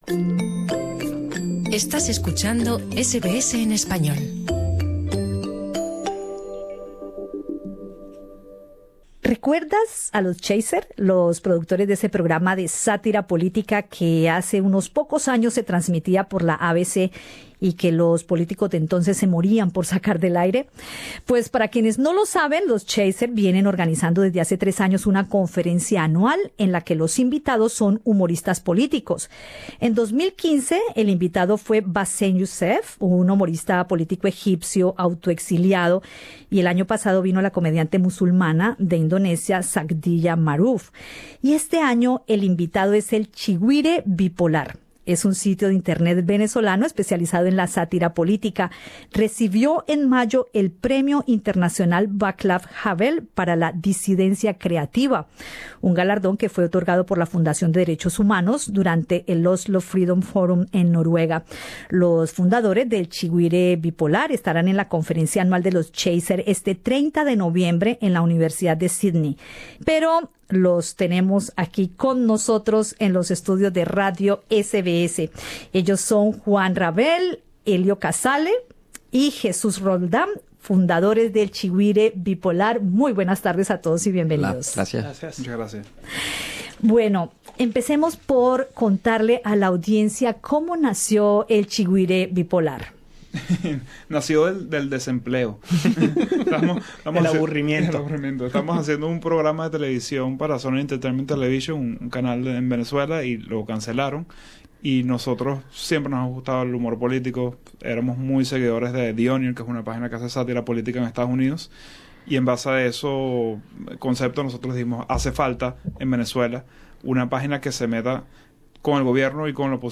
Productores de Chigüire Bipolar en los estudios de Radio SBS Source